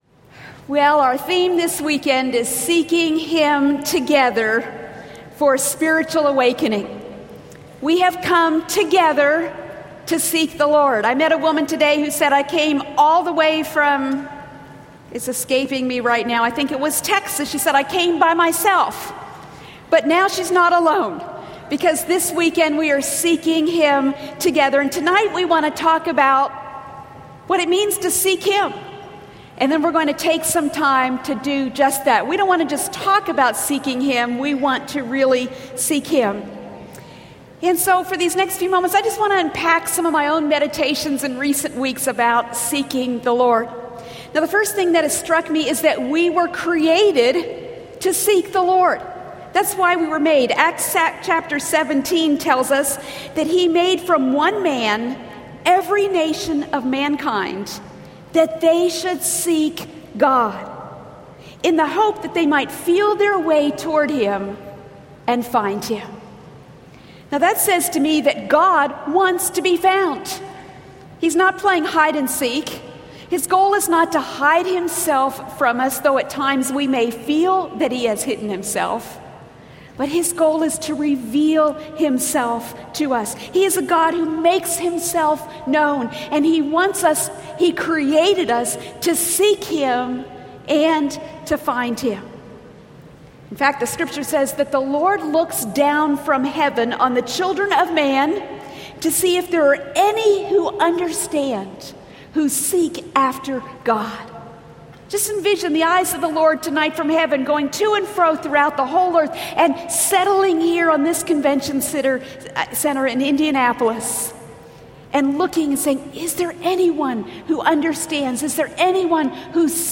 Seeking the Lord for Spiritual Awakening | True Woman '12 | Events | Revive Our Hearts